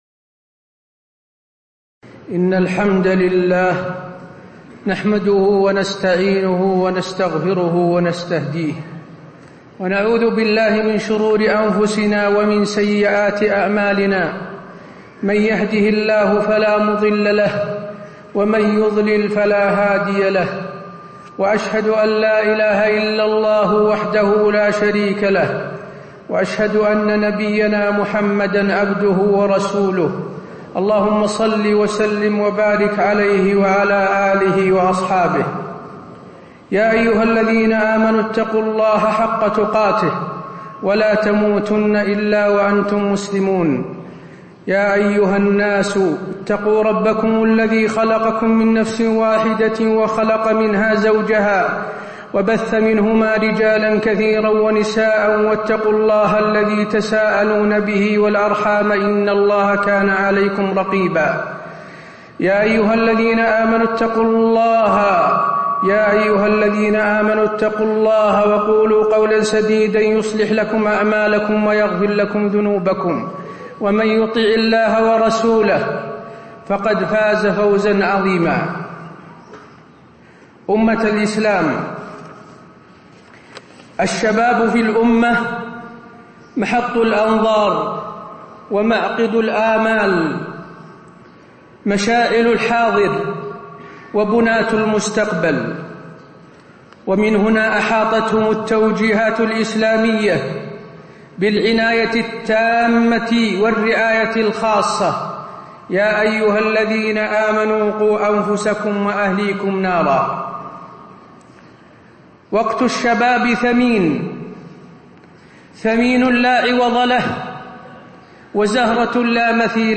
تاريخ النشر ١٢ ربيع الثاني ١٤٣٧ هـ المكان: المسجد النبوي الشيخ: فضيلة الشيخ د. حسين بن عبدالعزيز آل الشيخ فضيلة الشيخ د. حسين بن عبدالعزيز آل الشيخ وصايا مهمة لشباب الأمة